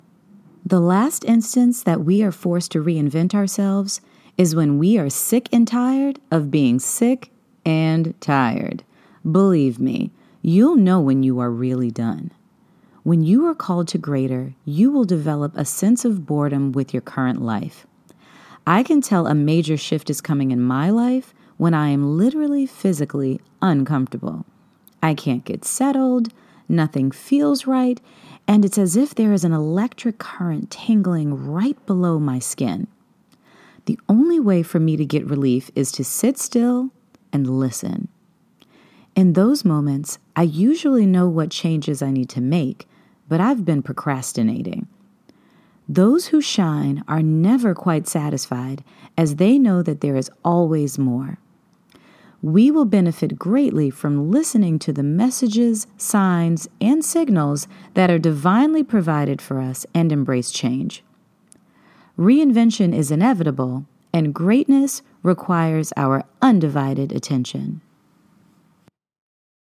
narration : women